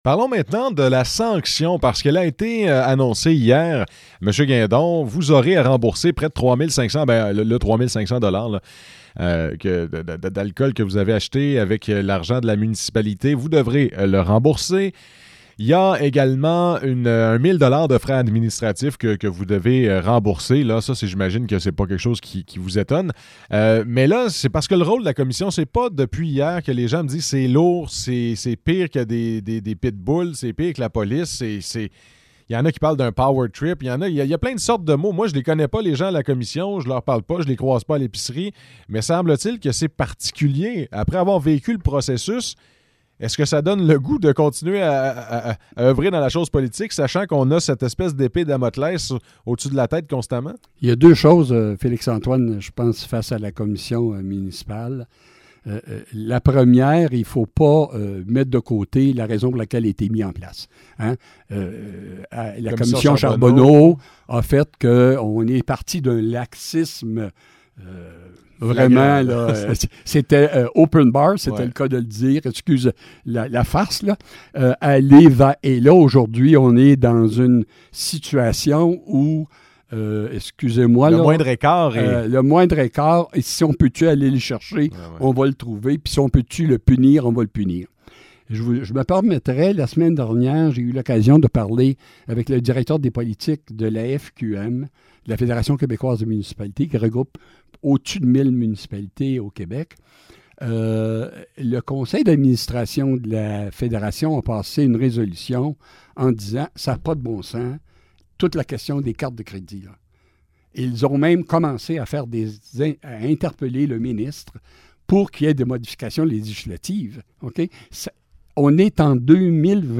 Suite de l'entrevue avec l'ancien maire de Denholm, qui a démissionné de ses fonctions après avoir plaidé coupable à des accusations de conduites avec facultés affaiblies, revient sur les manquements qui lui étaient reprochés et donne sa version des faits.